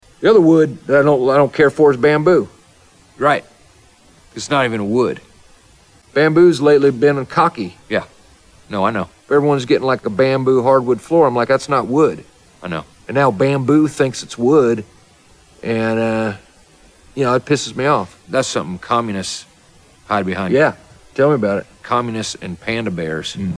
Category: Comedians   Right: Personal
Tags: Will Ferrell Will Ferrell impersonation George W. Bush George Bush Hbo show